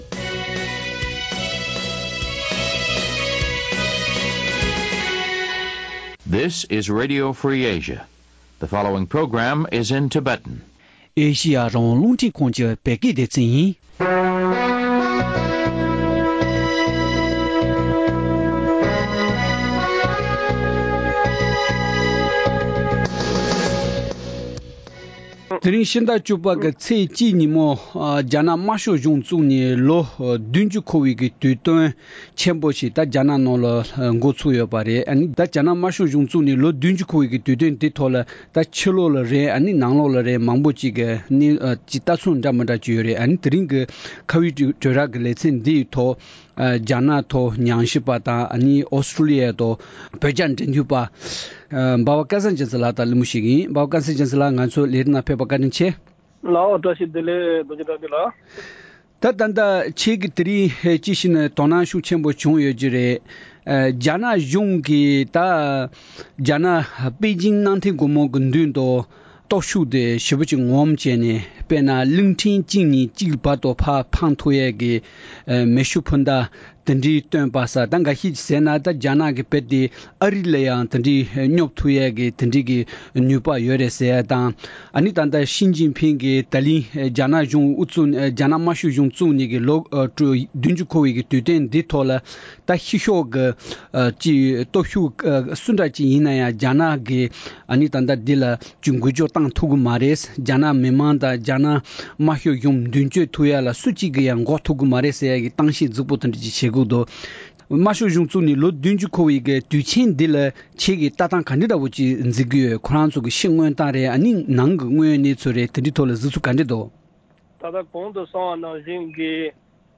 བོད་ནང་དུ་དམར་ཤོག་གཞུང་གིས་དབང་བསྒྱུར་བྱས་རྗེས་ད་ལྟ་བོད་མིའི་གནས་བབ་སྐོར་གླེང་མོལ།